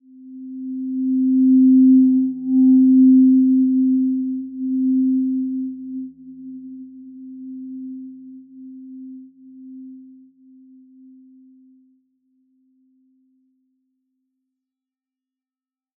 Simple-Glow-C4-mf.wav